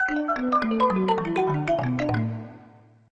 mallet_percussion_descending.ogg